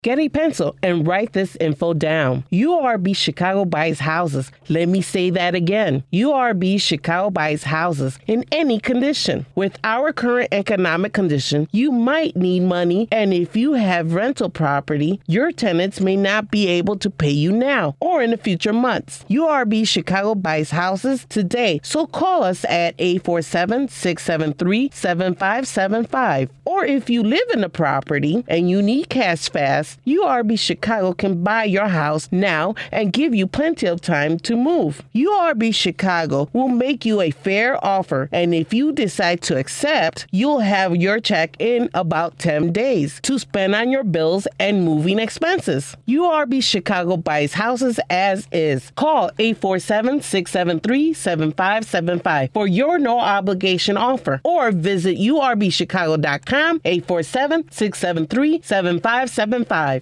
URB Chicago radio commericial